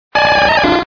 Cri de Carapuce dans Pokémon Diamant et Perle.